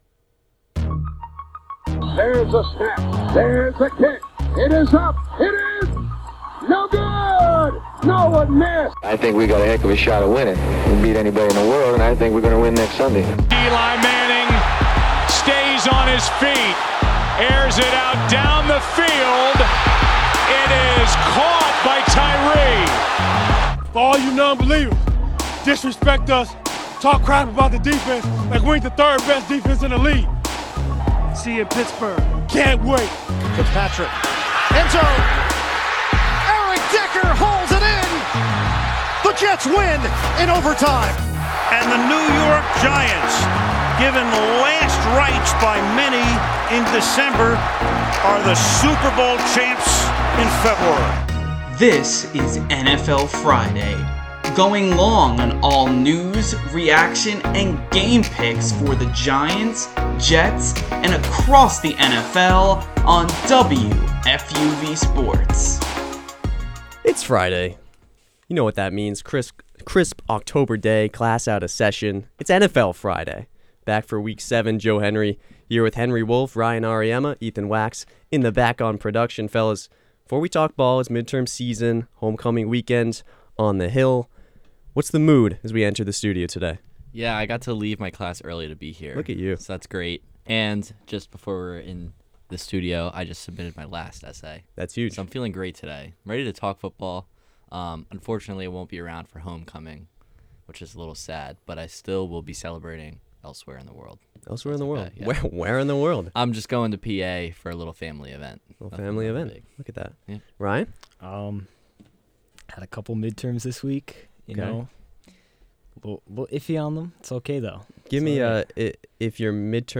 Our New York football beat reporters bring you up to speed on the latest action here at home around the league before Sunday.